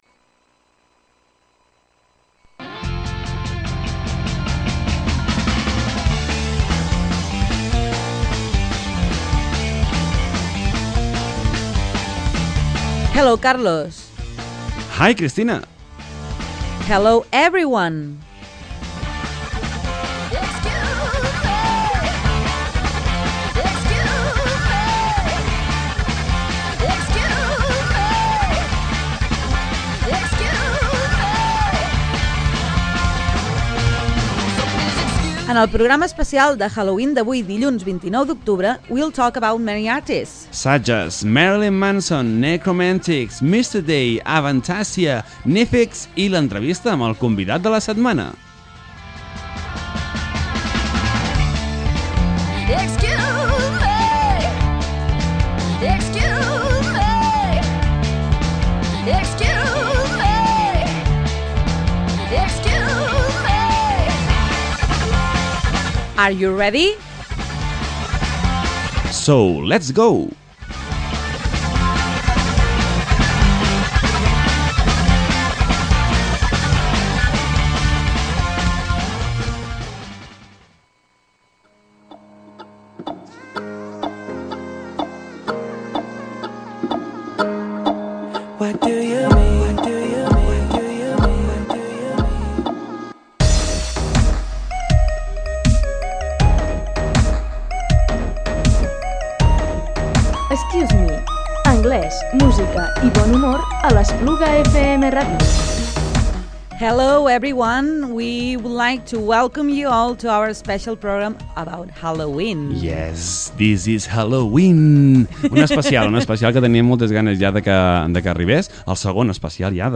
Vestim l’Excuse me? amb els típics fils musicals de terror, però també amb noves propostes que us volem donar a conèixer. En primer lloc, hem comentat algunes de les expressions que normalment no es pronuncien bé.